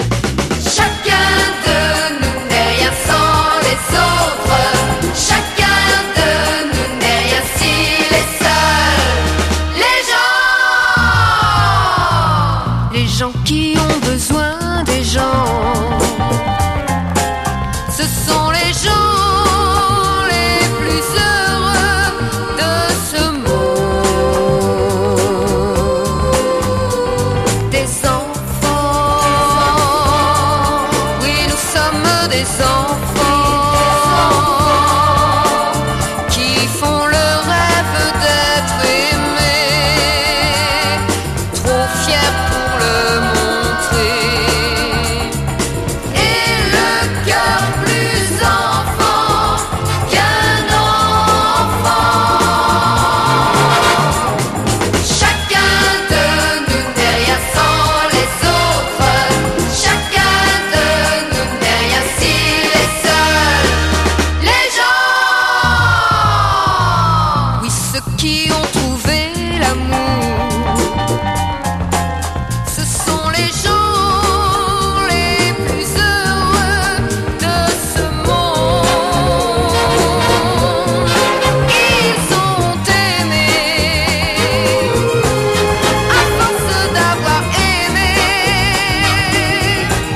グルーヴィーなフレンチ・ソフトロック/ガール・ポップ・ソウル！